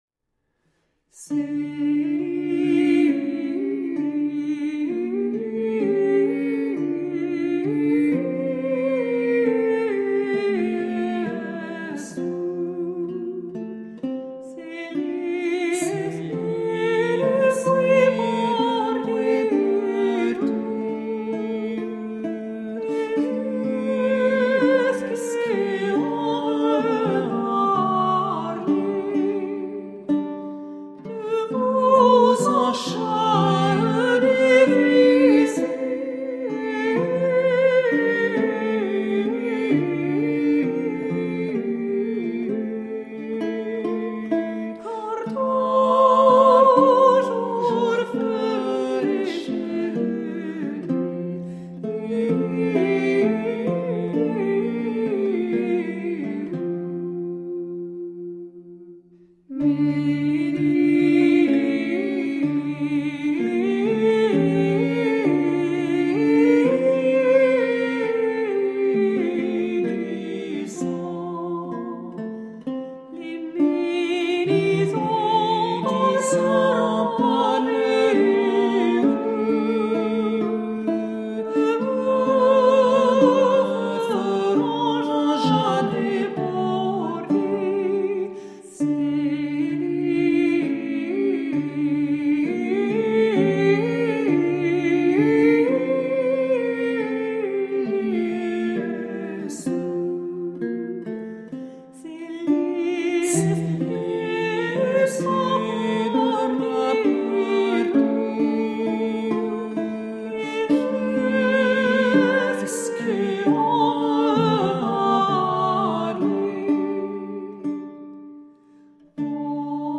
Late-medieval vocal and instrumental music